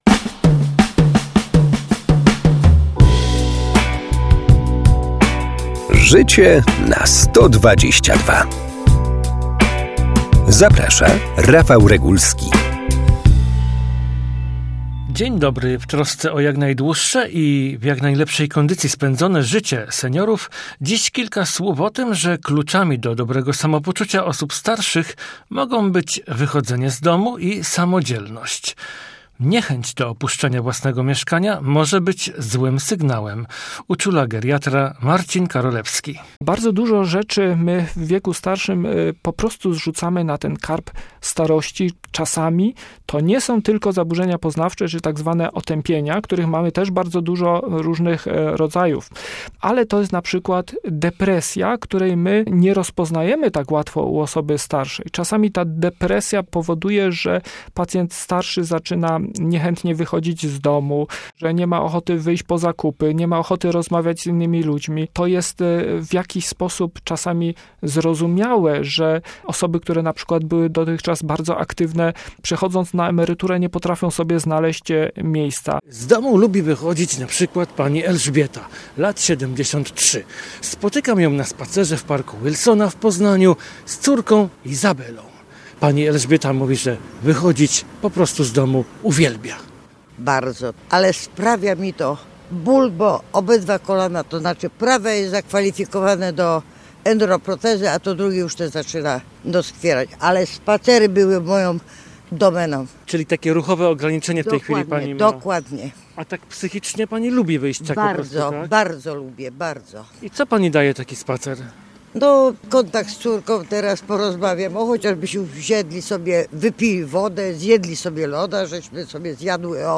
Wychodzenie z mieszkania, z domu i samodzielność - to dwa spośród wielu czynników budujących psychikę seniora (nie tylko jego oczywiście). W tym odcinku ustami lekarza przestrzegamy bliskich seniorów przed nadopiekuńczością.